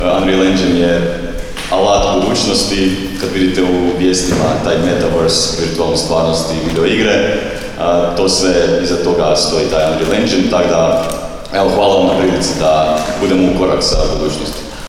U Novskoj uručena Uvjerenja polaznicima edukacije “Objektno orijentirano programiranje u izradi videoigara”
Da su Novska i Sisačko-moslavačka županija ukorak s vremenom potvrdio je i jedan od polaznika edukacije